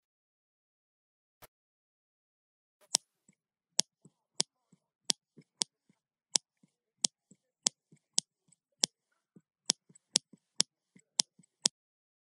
Подборка включает четкие и реалистичные записи, которые подойдут для видео, подкастов или звукового оформления.
Ножницами стригут волосы